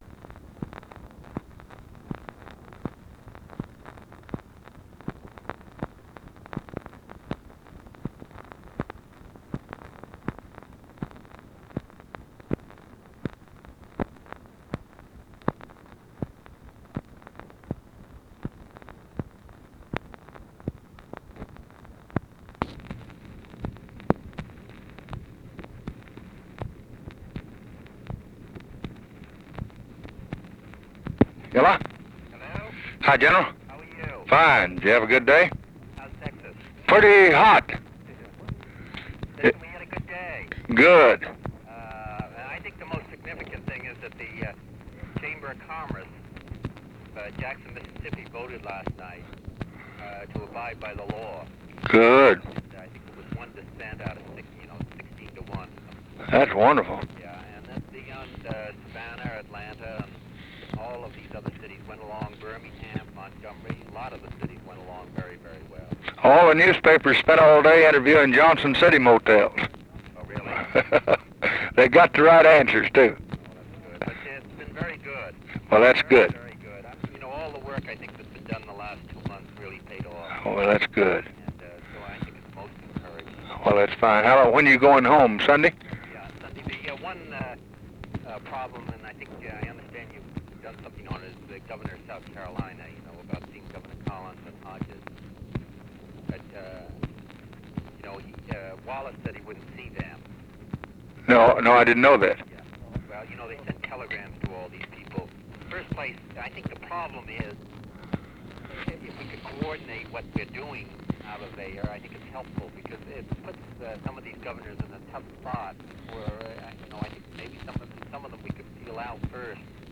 Conversation with ROBERT KENNEDY and JACQUELINE KENNEDY, July 4, 1964
Secret White House Tapes